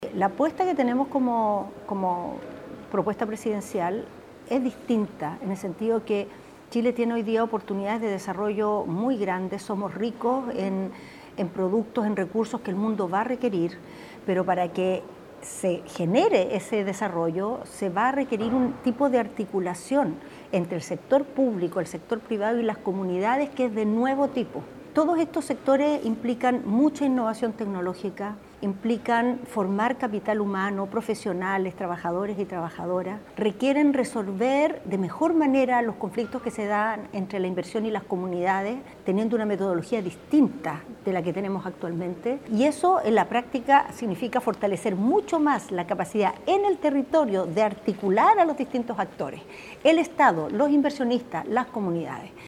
Durante la entrevista, que se centró en el desarrollo de los territorios, la militante del PPD indicó que su apuesta se basa en tres ejes: “el primero es progreso, el segundo se llama bienestar compartido, y el tercero es el tema de la seguridad. En todos hay dimensiones que tienen que ver con lo regional”.